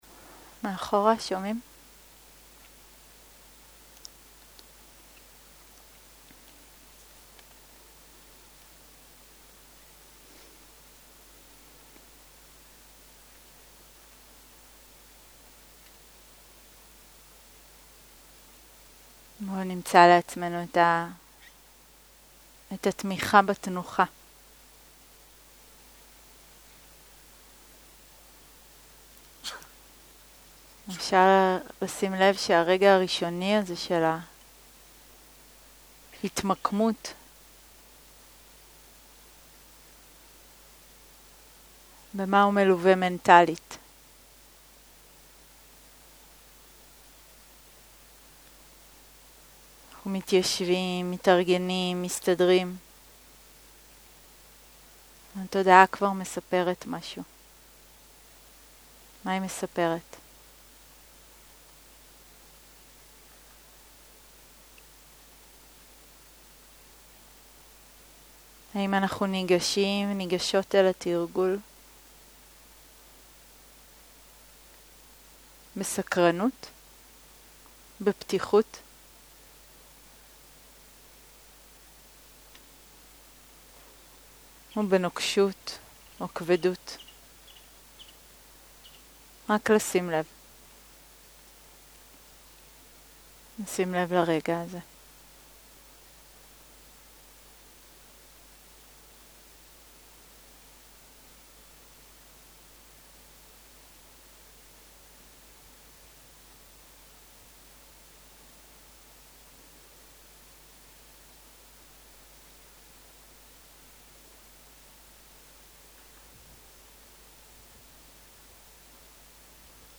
מדיטציה מונחית - חמשת המכשולים
סוג ההקלטה: מדיטציה מונחית